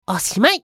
少年系ボイス～戦闘ボイス～